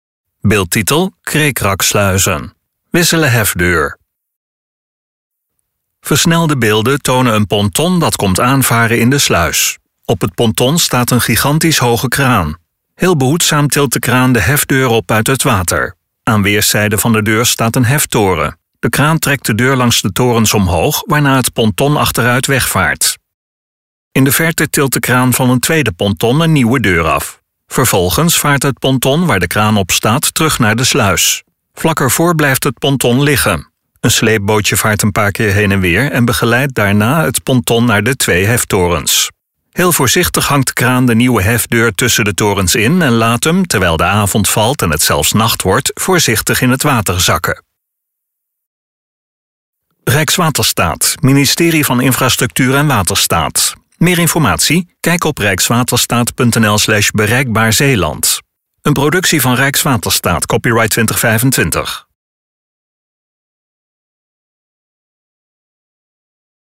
RUSTIGE MUZIEK TOT HET EIND VAN DE VIDEO (In de verte tilt de kraan van een tweede ponton een nieuwe deur af.